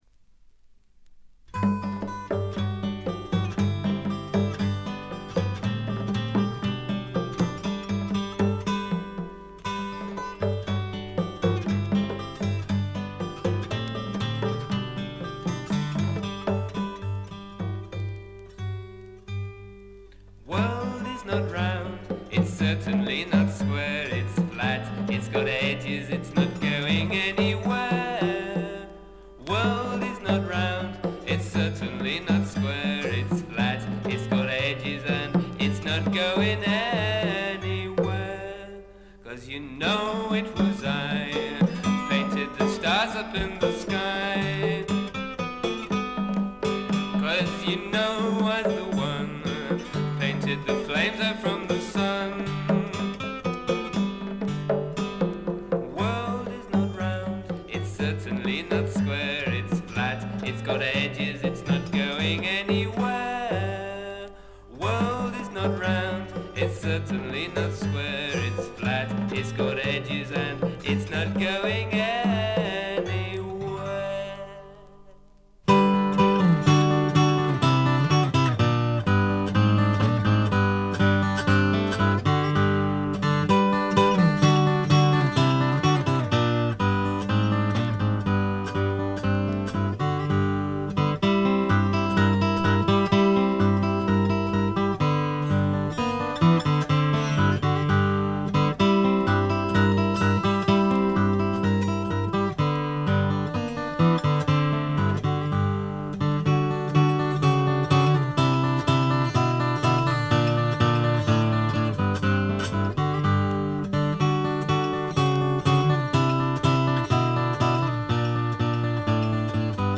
わずかなノイズ感のみ。
ラグ、ブルースを下地にしながらも超英国的なフォークを聴かせてくれるずばり名盤であります。
英国のコンテンポラリーフォーク／アシッドフォーク基本盤。
試聴曲は現品からの取り込み音源です。
Vocals, Guitar